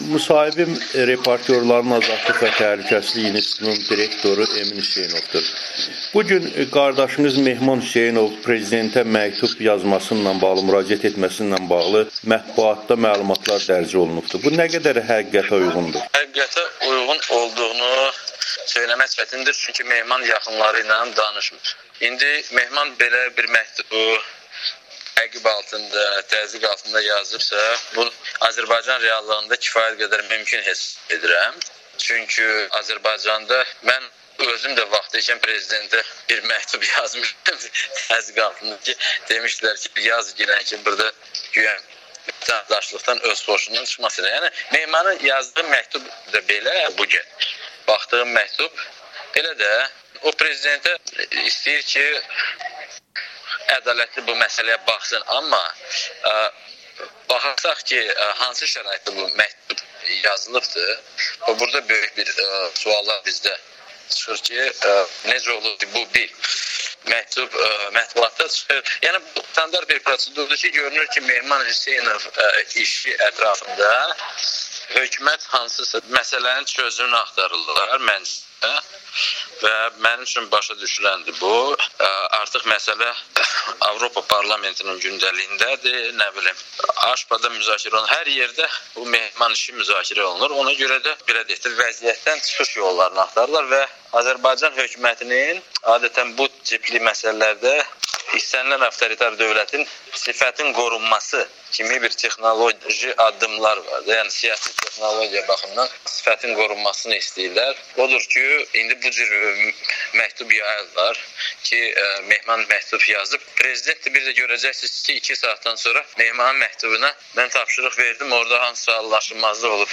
Amerikanın Səsinə müsahibəsində bildirib.